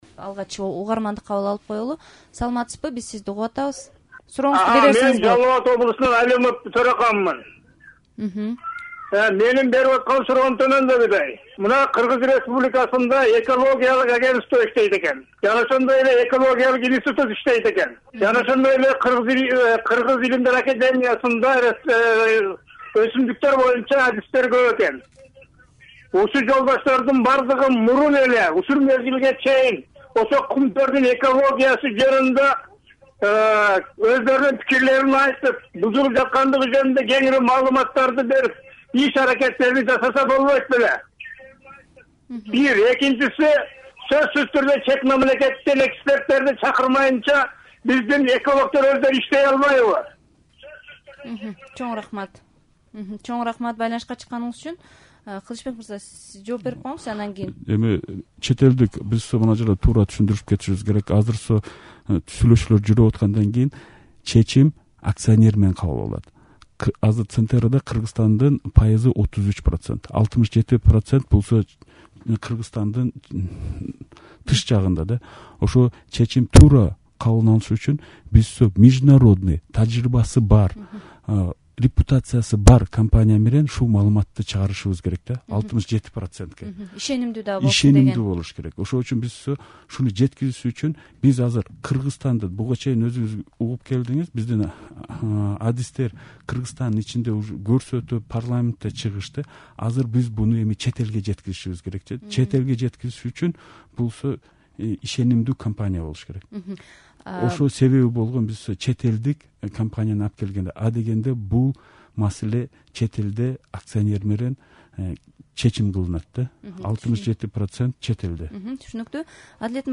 Кумтөр тууралуу талкуу (1-бөлүк)